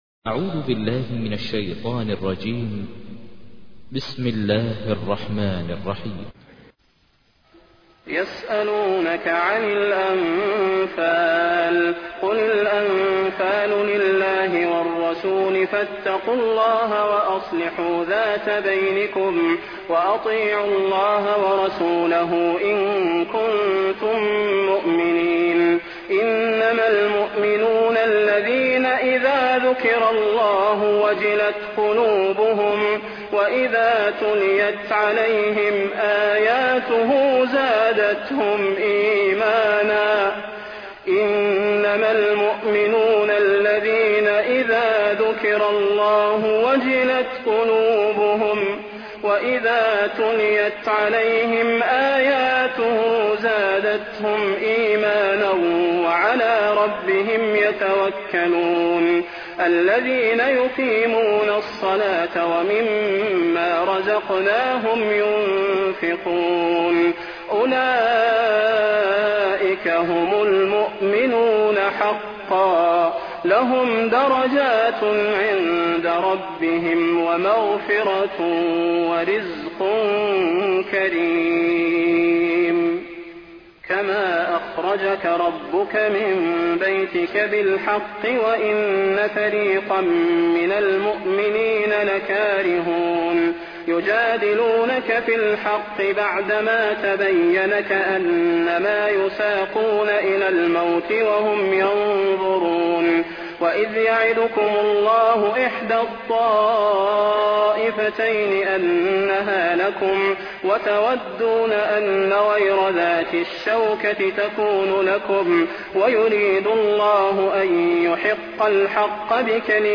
تحميل : 8. سورة الأنفال / القارئ ماهر المعيقلي / القرآن الكريم / موقع يا حسين